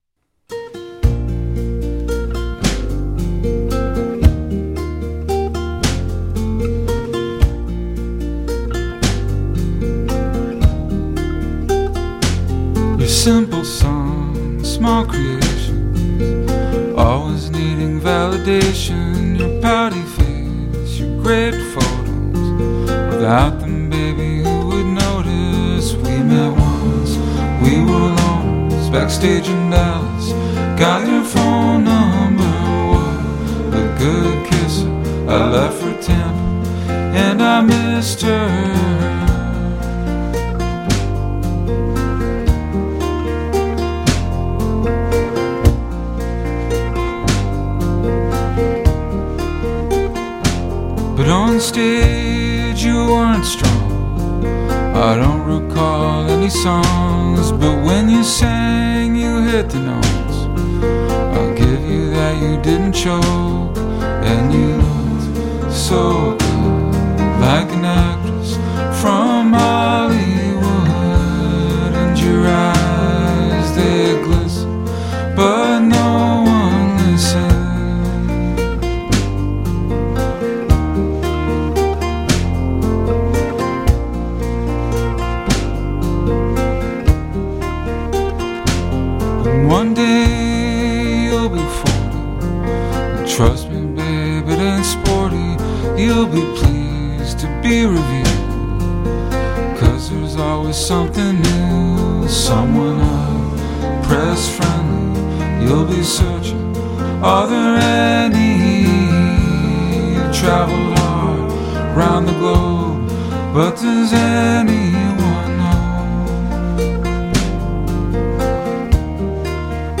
风格：迷幻摇滚, 后摇, 流行